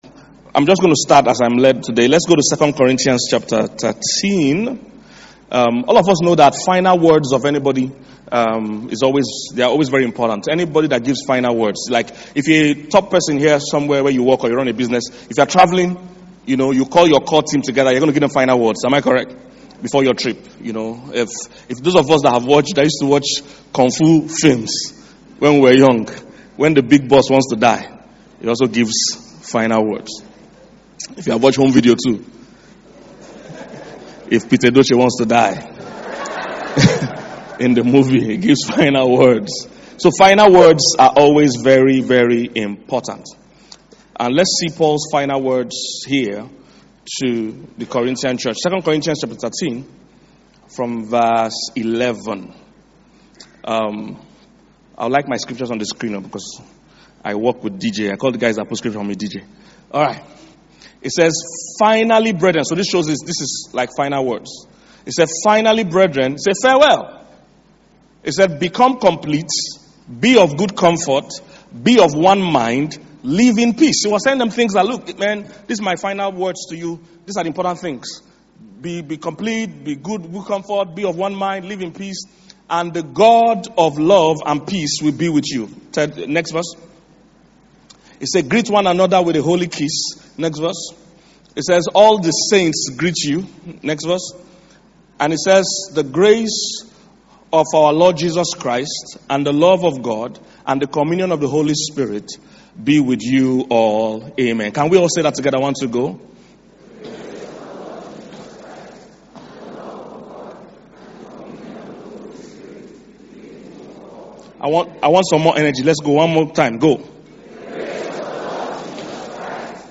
Enjoy the entire message! and stay blessed always.